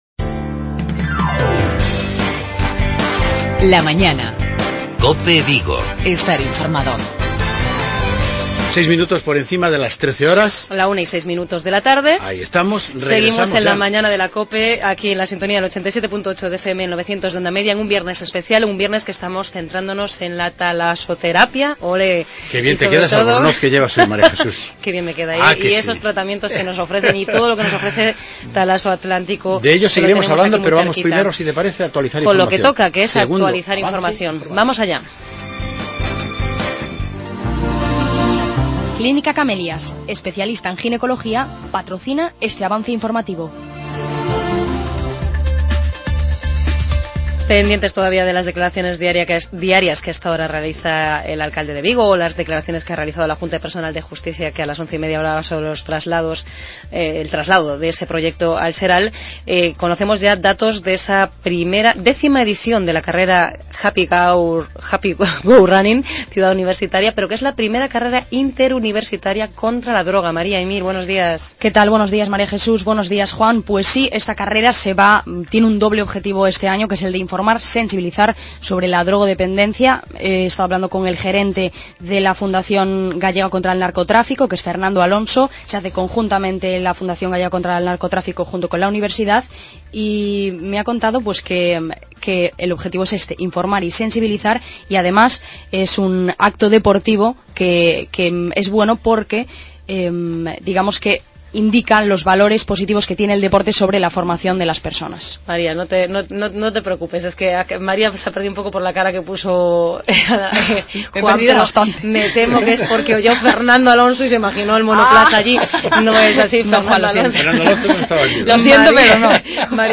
Continuamos en los micrófonos de COPE Vigo abordando los beneficios y provechos de la talasoterapia.